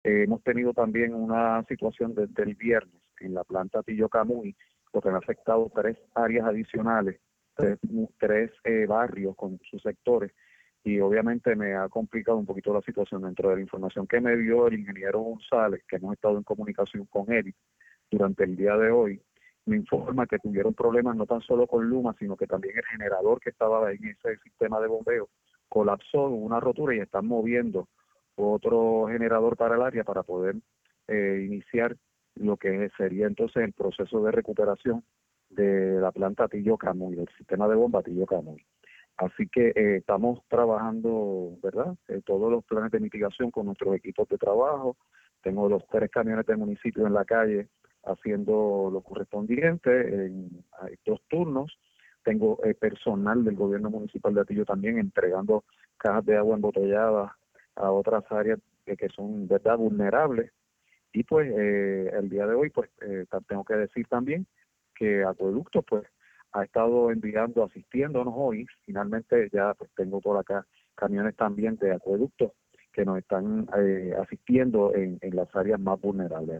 Dentro de la información que me dio el ingeniero (y presidente de la AAA, Luis) González, que hemos estado en comunicación con él, durante el día de hoy me informa que tuvieron problemas no tan solo con Luma, sino que también el generador que estaba ahí en ese sistema de bombeo colapsó, hubo una rotura y están moviendo otro generador para el área para poder iniciar lo que sería entonces el proceso de recuperación de la planta Hatillo-Camuy“, indicó el alcalde de Hatillo, Carlos Román.